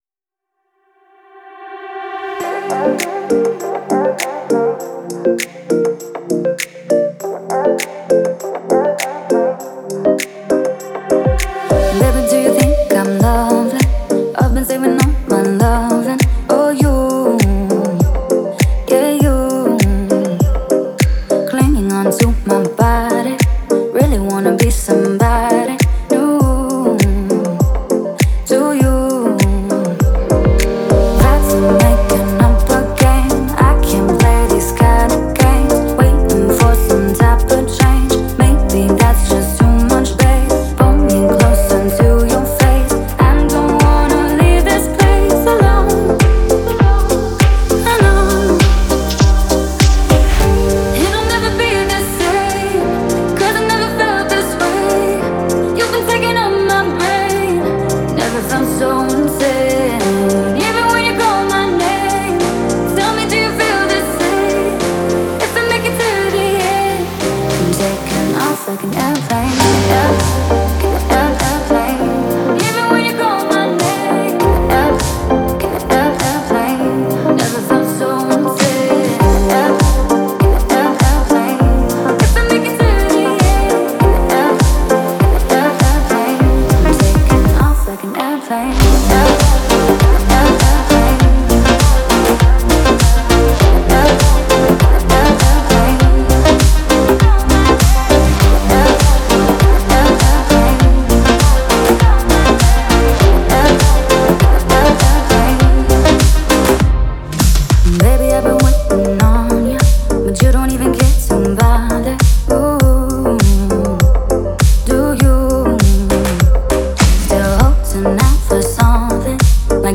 это атмосферная трек в жанре chill-out